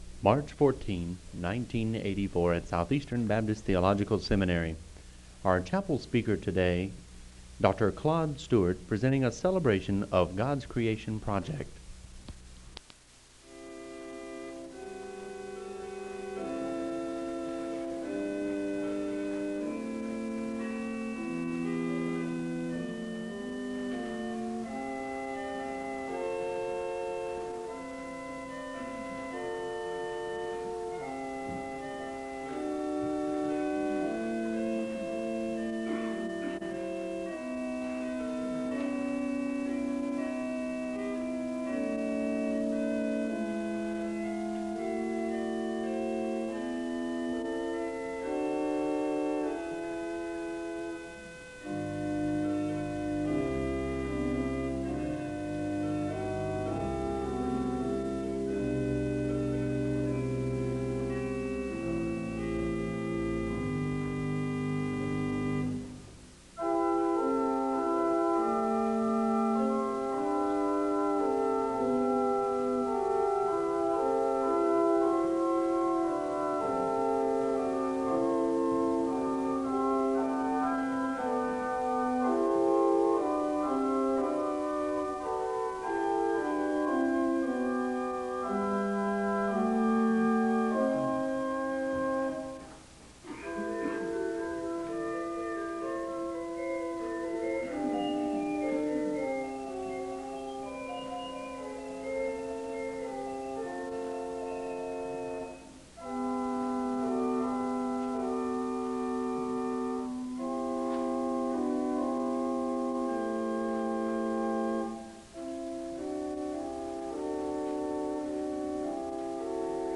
The service begins with organ music (00:00-03:35). The choir sings a song of worship (03:36-07:23).
The choir sings a song of worship (17:17-23:31).
A soloist sings a song of worship (28:33-33:10).
New Testament--Criticism, interpretation, etc. Responsive worship Bible.